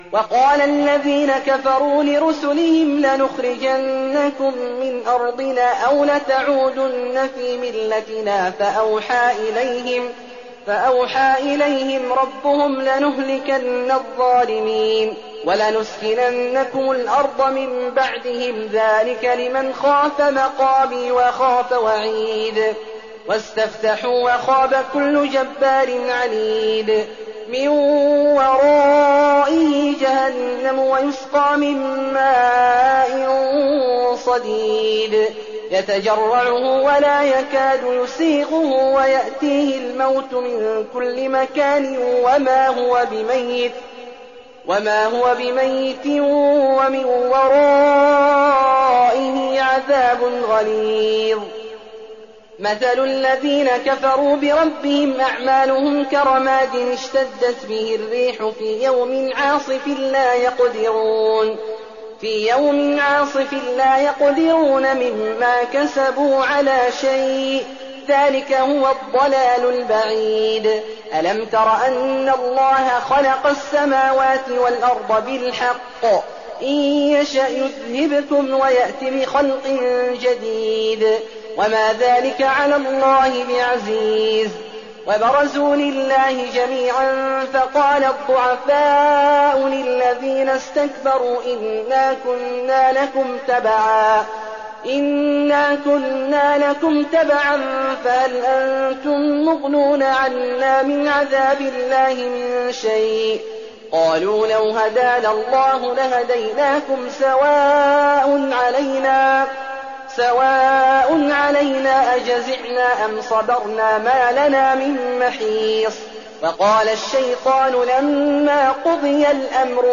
تراويح الليلة الثالثة عشر رمضان 1419هـ من سور إبراهيم (13-52) الحجر كاملة و النحل (1-52) Taraweeh 13th night Ramadan 1419H from Surah Al-Hijr and An-Nahl > تراويح الحرم النبوي عام 1419 🕌 > التراويح - تلاوات الحرمين